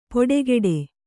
♪ poḍegeḍe